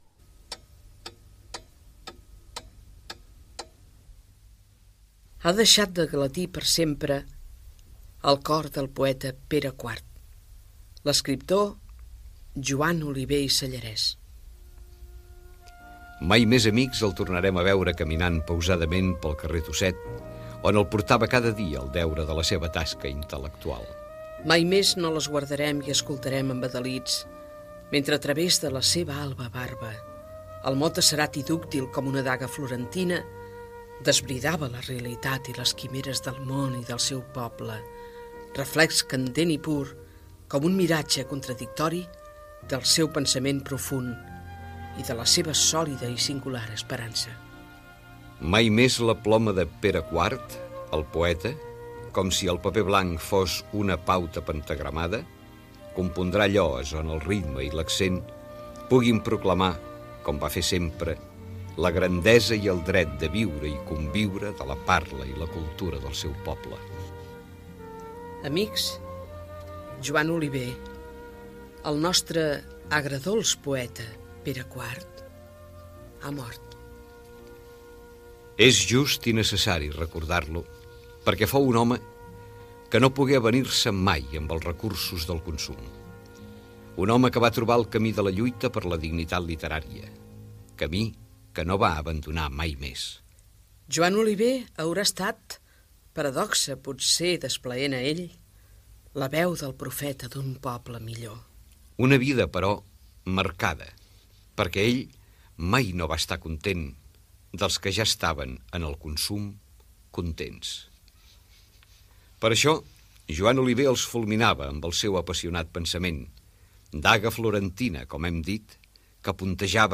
FM
Aquesta necrològica es va enregistrar el dia 5 de març del 1982 i es va guardar per al moment que morís Joan Oliver.